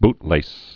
(btlās)